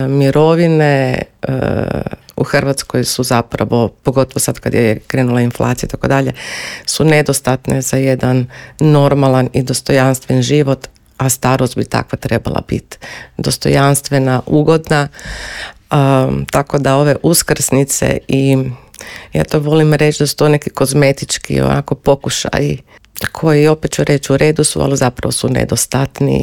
Gostujući u Intervjuu Media servisa objasnila je da je gerontologija znanstvena disciplina koja u svom fokusu ima osobe starije životne dobi.